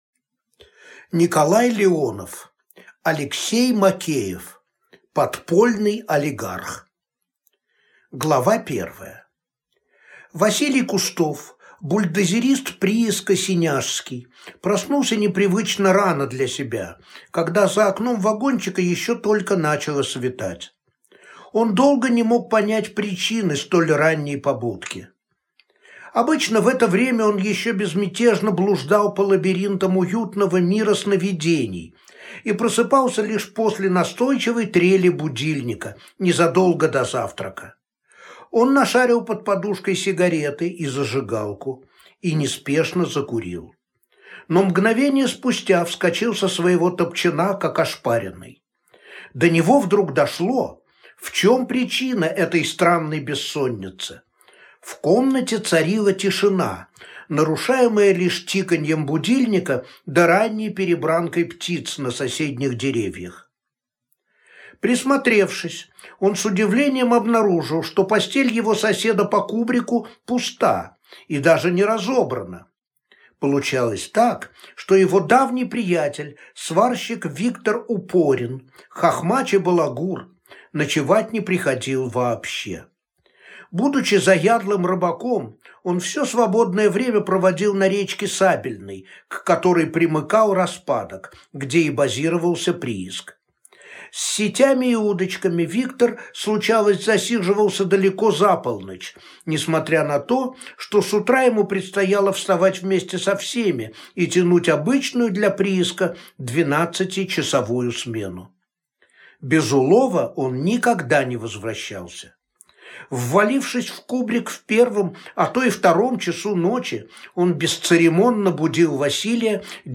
Аудиокнига Подпольный олигарх | Библиотека аудиокниг
Прослушать и бесплатно скачать фрагмент аудиокниги